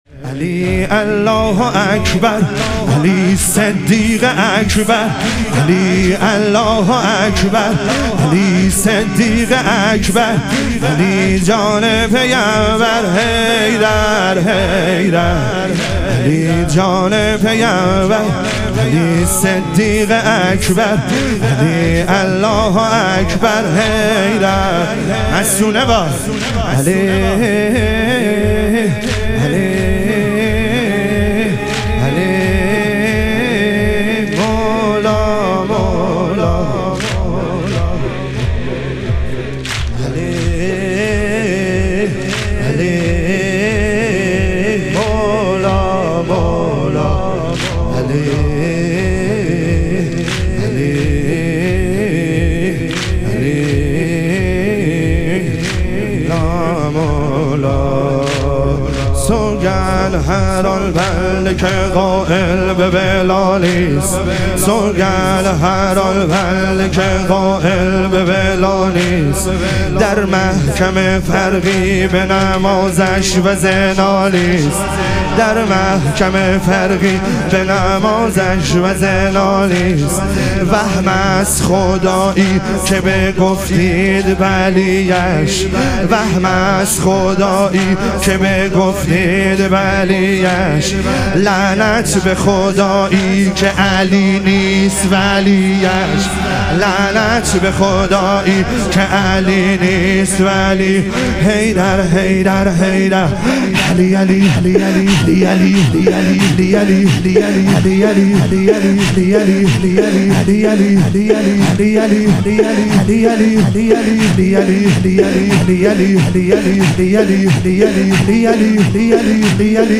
ایام فاطمیه دوم - واحد